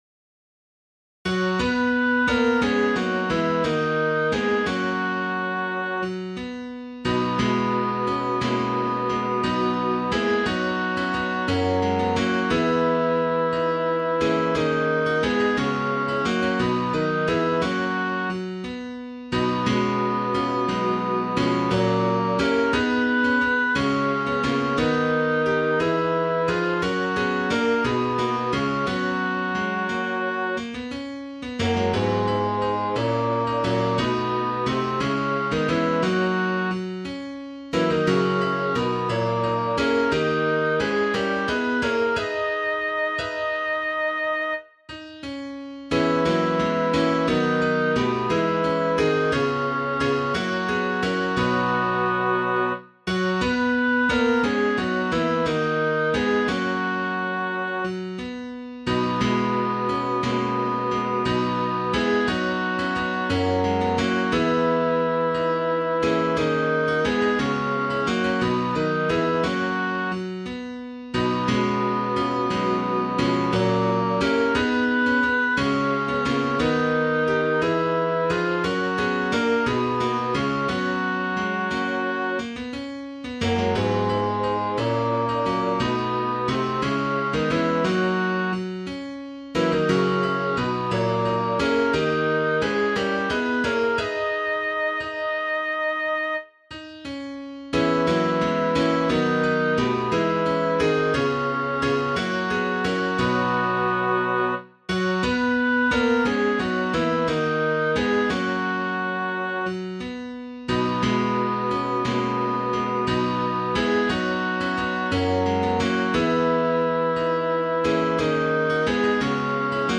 i himmelen-bas.mp3